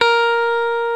FENDER STRAT 7.wav